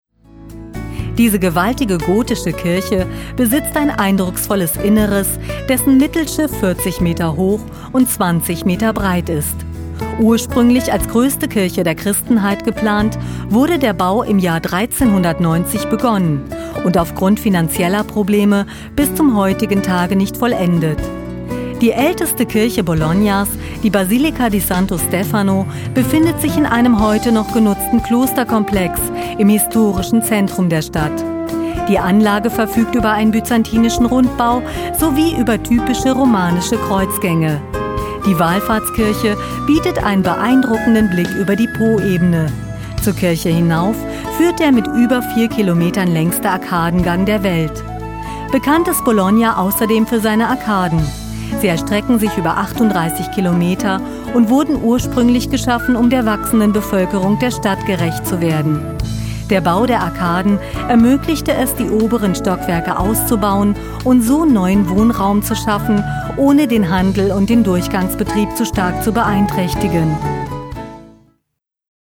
Sprechprobe: eLearning (Muttersprache):
german female voice over artist, young voice